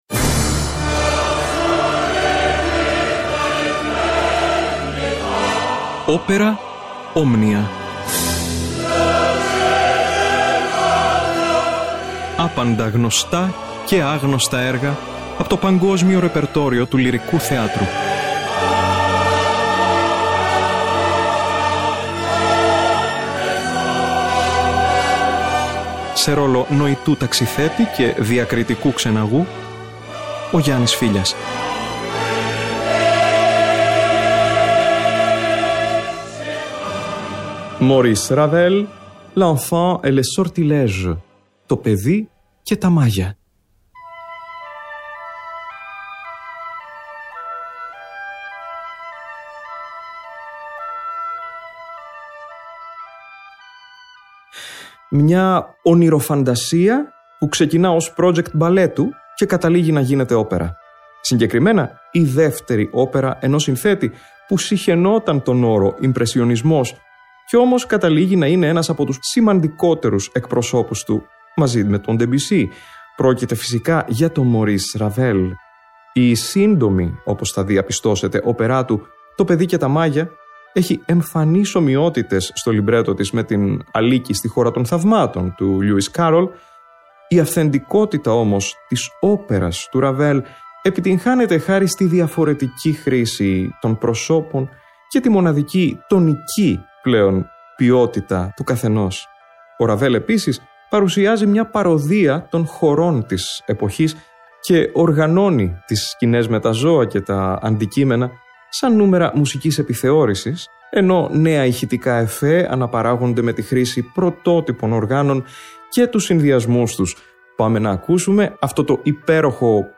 Λυρική φαντασία σε δύο μέρη.